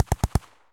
sounds_ar_burst_02.ogg